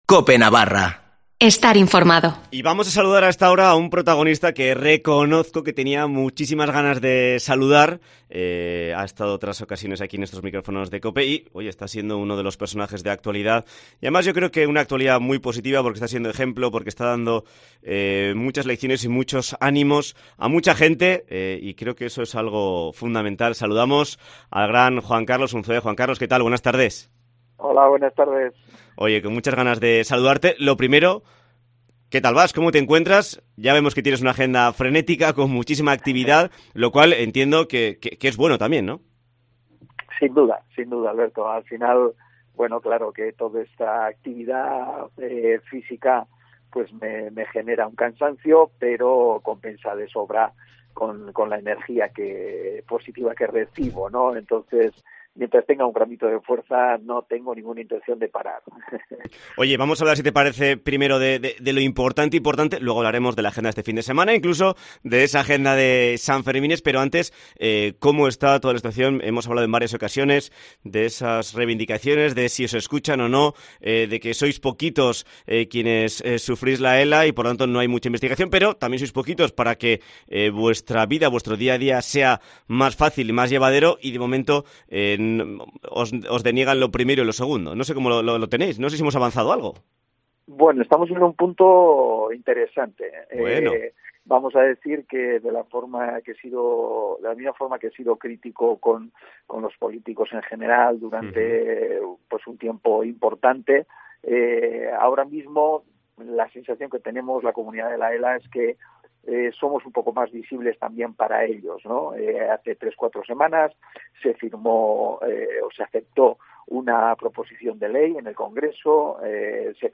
Entrevista a Juan Carlos Unzué